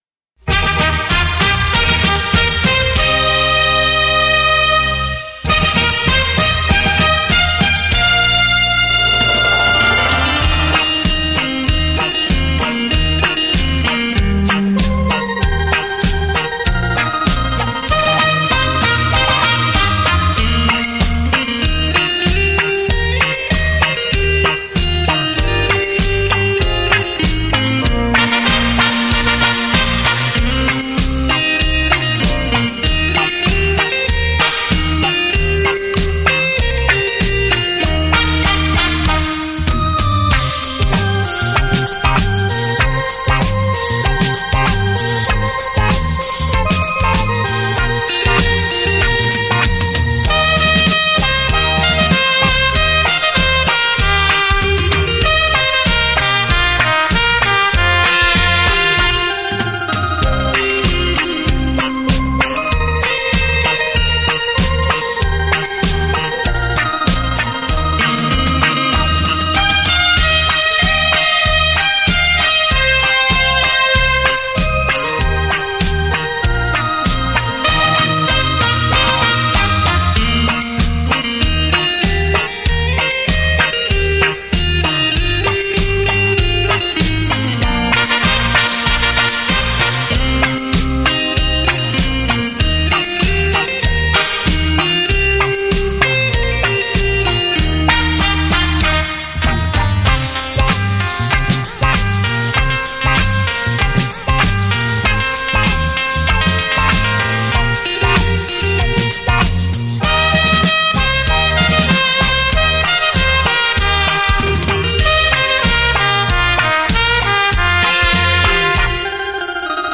德国真空管录音技术
永恒的音韵，极致的声色，浪漫的格调，唯美而煽情，深沉而丰润。
那悠扬清亮的音色，无不是心灵渴望放松休憩的一种恰到好处的绝好享受！